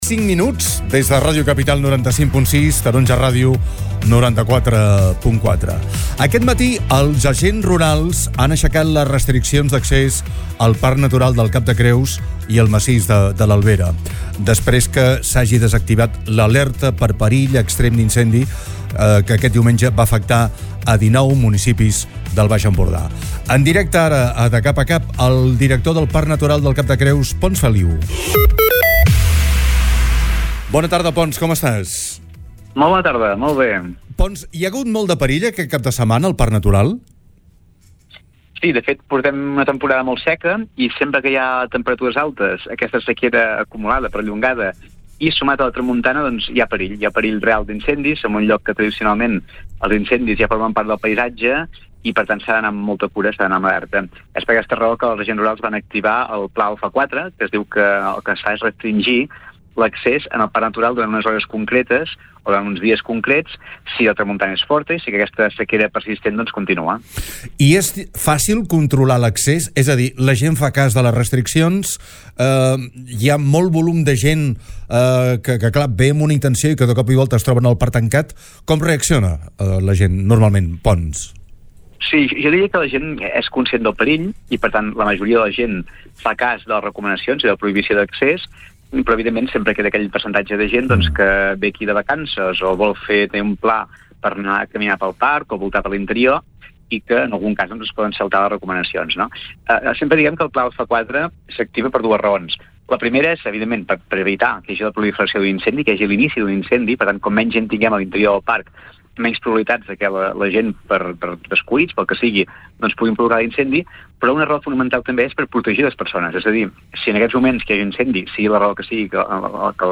entrevistat al programa